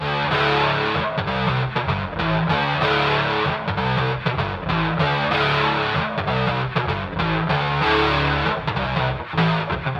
描述：摇滚电吉他
Tag: 96 bpm Rock Loops Guitar Electric Loops 1.68 MB wav Key : Unknown